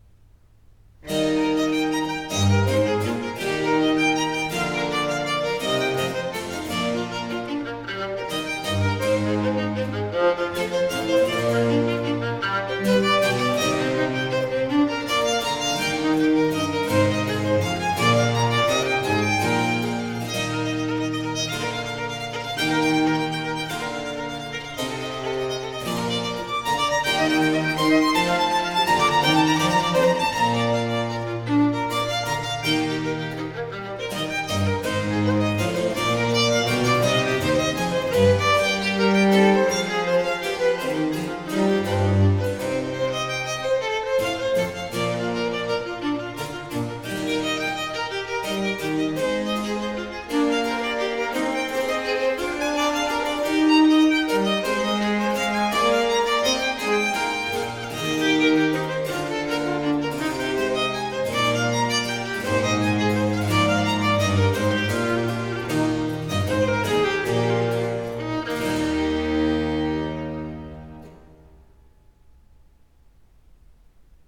Vivace